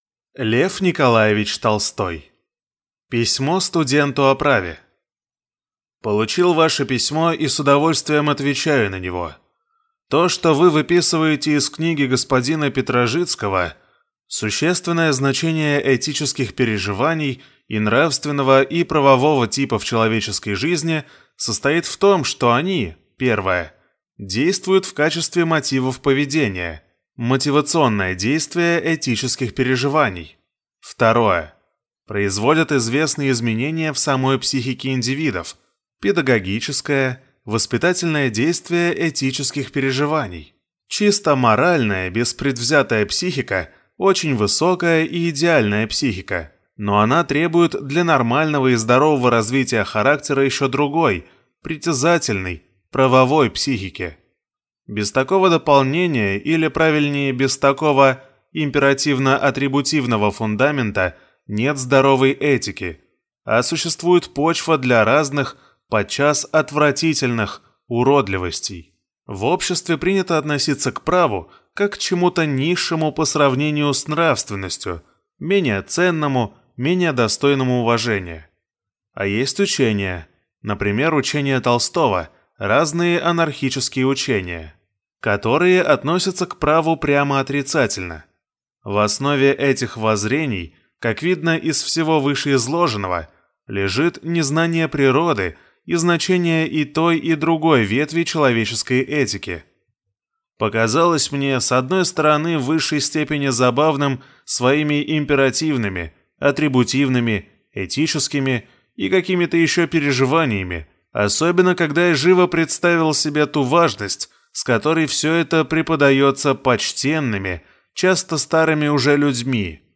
Аудиокнига Письмо студенту о праве